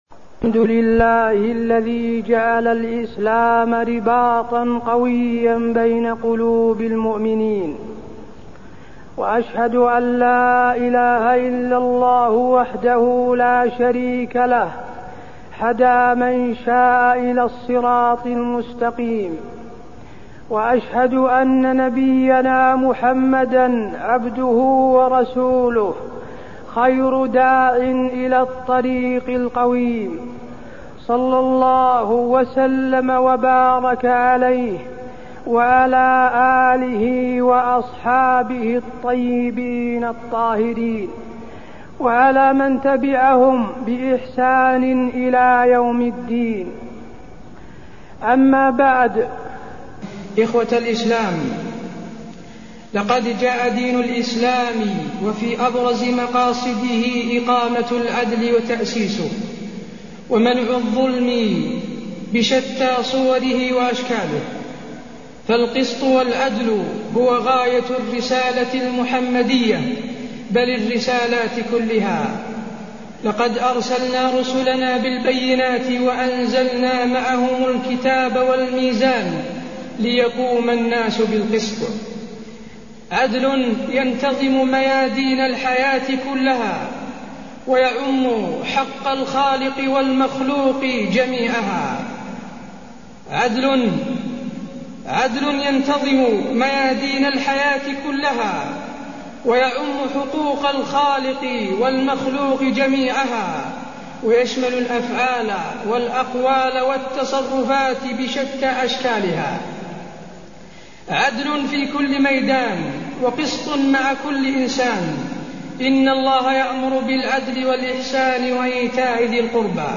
تاريخ النشر ٢٦ محرم ١٤٢٢ هـ المكان: المسجد النبوي الشيخ: فضيلة الشيخ د. حسين بن عبدالعزيز آل الشيخ فضيلة الشيخ د. حسين بن عبدالعزيز آل الشيخ الشهادة وقول الزور